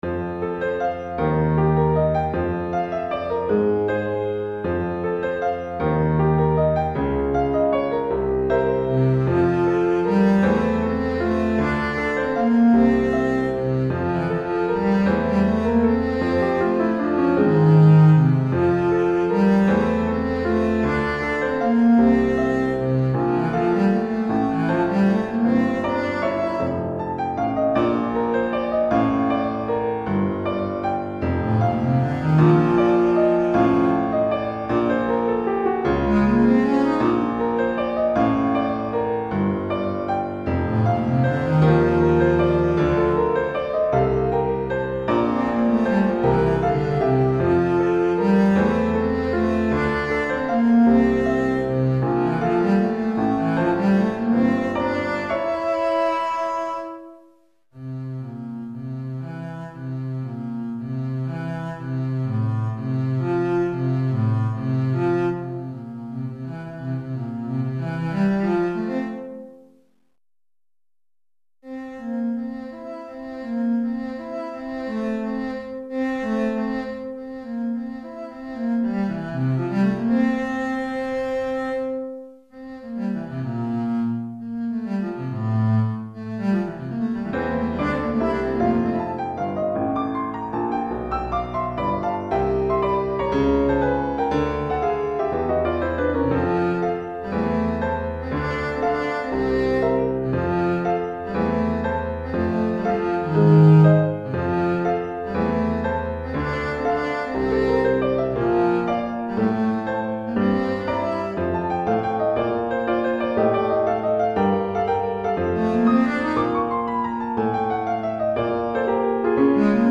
Contrebasse et Piano